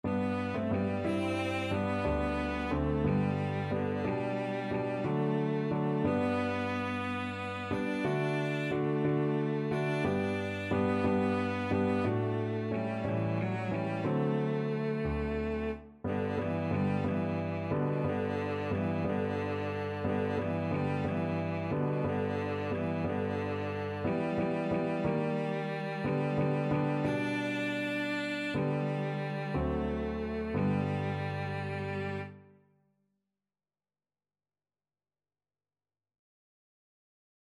Cello version
6/8 (View more 6/8 Music)
Classical (View more Classical Cello Music)